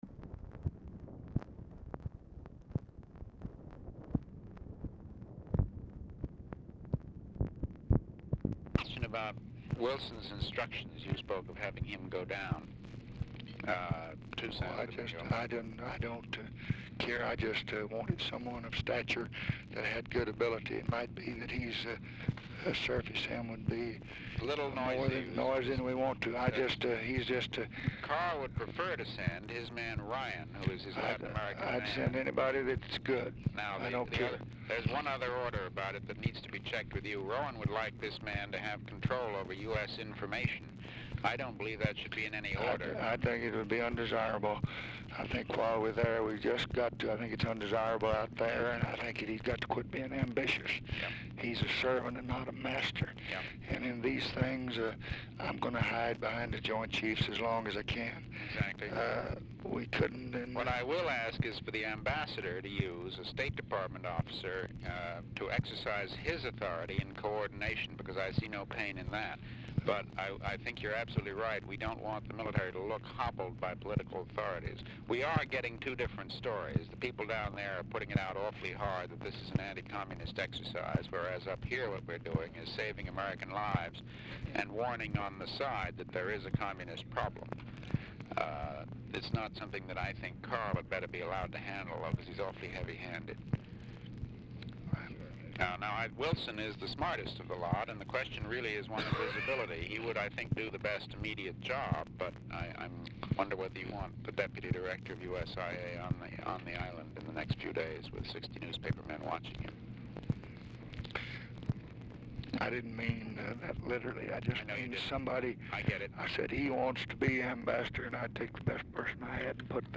RECORDING STARTS AFTER CONVERSATION HAS BEGUN; LBJ IS HOARSE
Format Dictation belt
Specific Item Type Telephone conversation Subject Communist Countries Defense Diplomacy Latin America Press Relations Public Relations Vietnam Vietnam Criticism